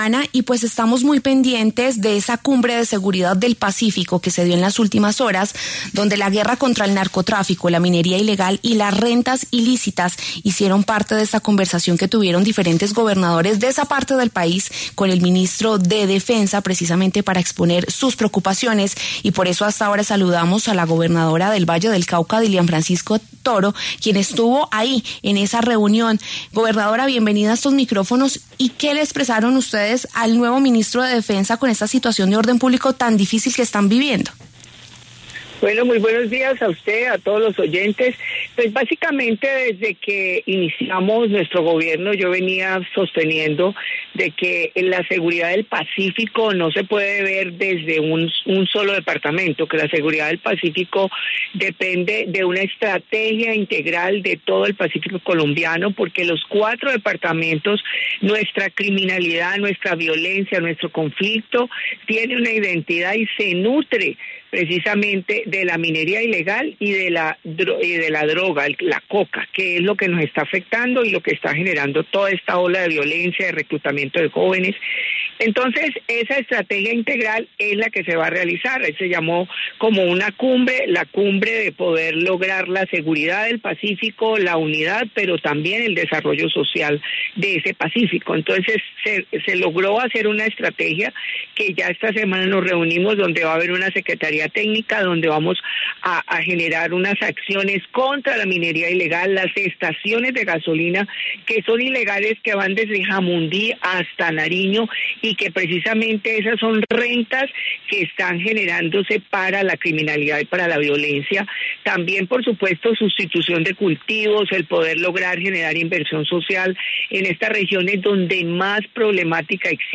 La gobernadora del Valle del Cauca, Dilian Francisca Toro, habló en W Fin de Semana sobre las nuevas medidas ante la ola de violencia en el suroccidente colombiano.